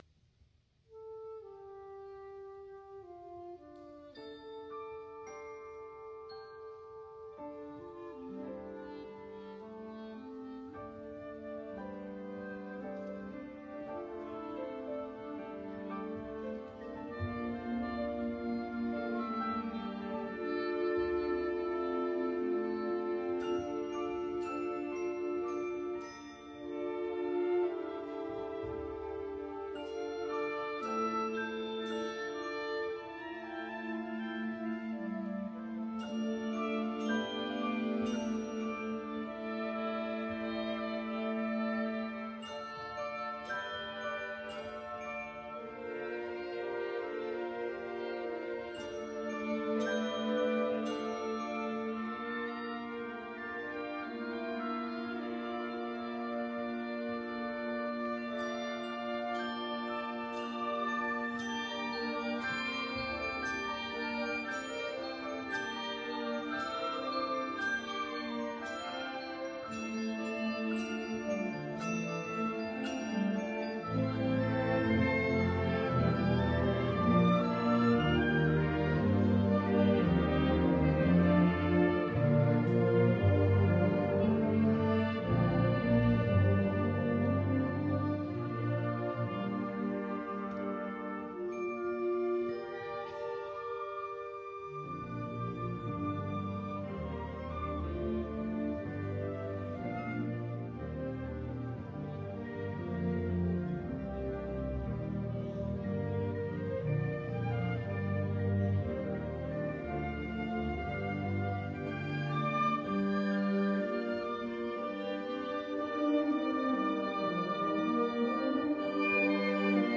編成：吹奏楽
Piano
Marimba
[Percussion] Tam-tam, Bass Drum